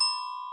glock_B_4_2.ogg